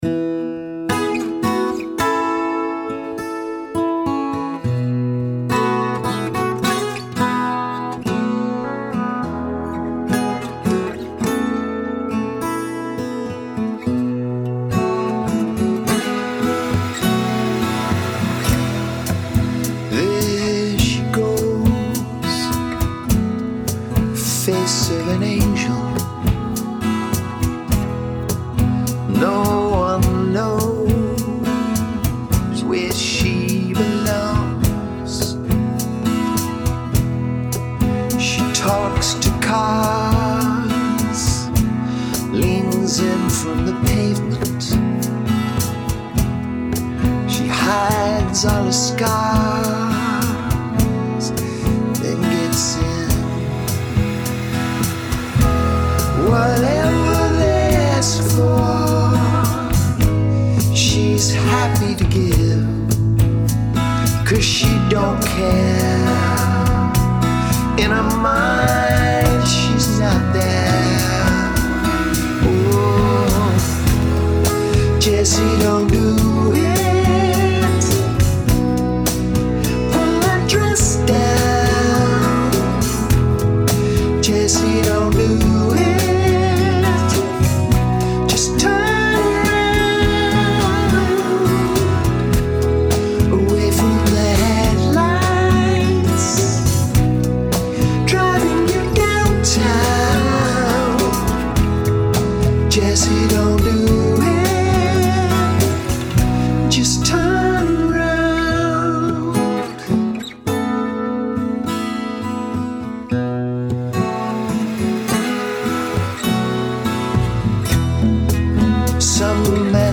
Singing Showreel
Warm, friendly, and approachable.
Male
British RP
Neutral British
Friendly
Warm
Singing